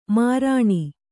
♪ mārāṇi